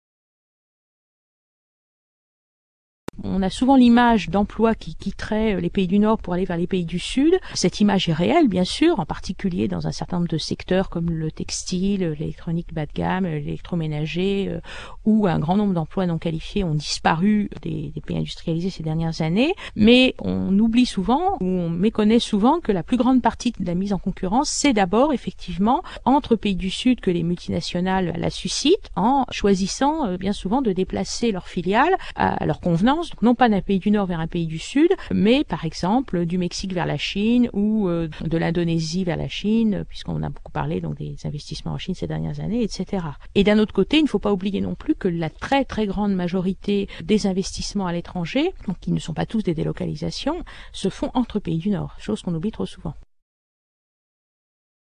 Cet interview a été réalisé à l’issue de la projection du documentaire de Marie France Collard, Ouvrières du monde, (Belgique, 2000. 52’).